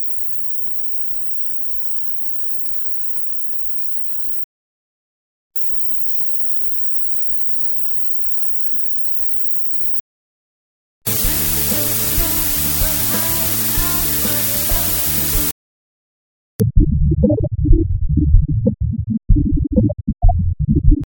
Методика следующая: был взят кусок исходного файла 24 бита, понижен уровень до минус 96 дБ и сконвертирован в 16 бит с дитером (гауссовского распределения с лёгким шейпингом), из 16-тибитного файла были получены два файла мр3 при помощи Any Audio Converter, затем файл 16 бит с дитером и два мр3 были обратно нормализованы. В приложенном файле следующая последовательность отрывков: 16 бит с дитером, 320 кб/с, 128 кб/с, 128 кб/с фраунгоферовский кодек.